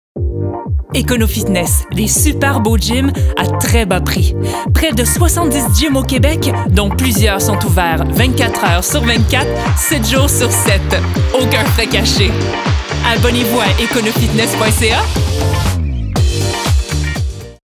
Timbre Grave - Médium
EconoFitness - Énergique - Souriante - Québécois naturel /
Annonceuse -Fictif 2023 0:18 713 Ko